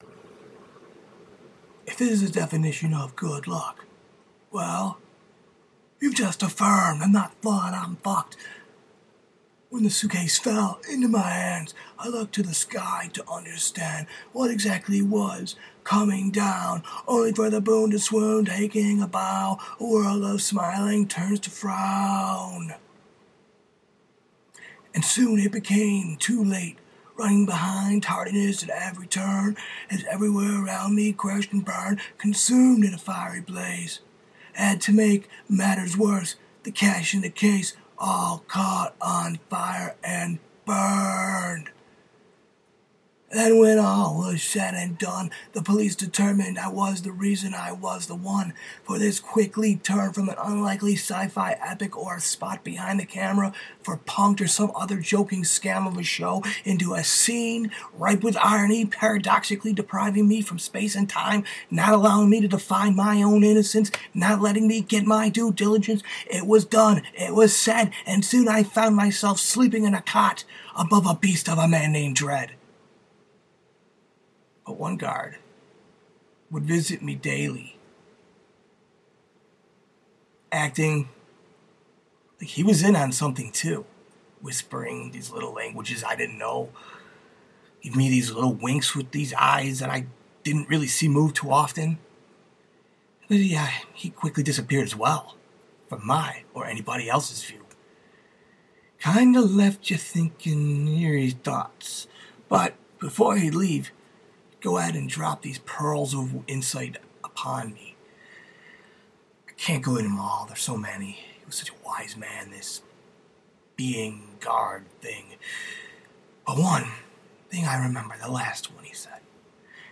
Reading, Prose Poetry, Skit